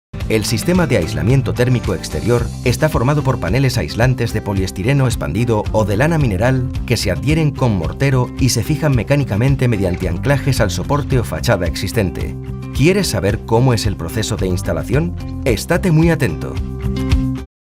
Apprentissage en ligne
Bonjour, je suis une voix off espagnole européenne à temps plein depuis 1992.
Microphones : Sennheiser 416, Rode NT1, NT2-A
Baryton
jc-t-spanish-e-learning-demo.mp3